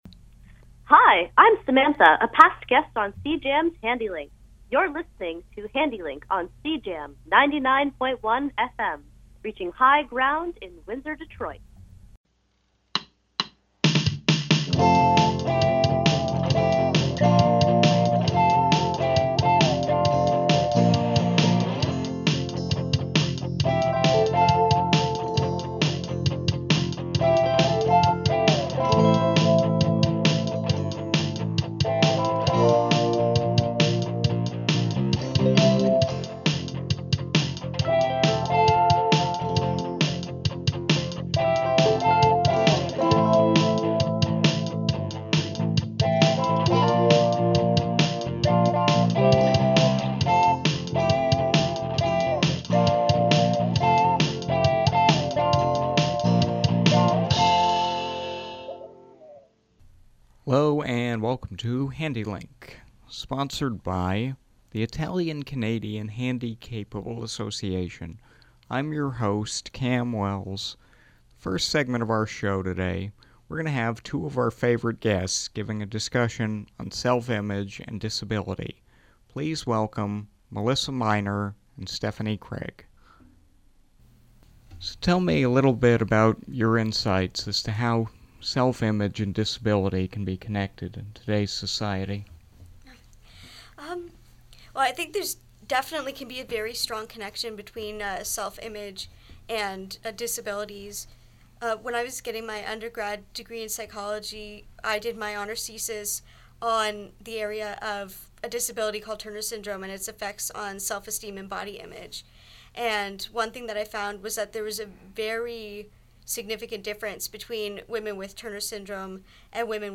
Handi-Link_-_Self_image_panel_.mp3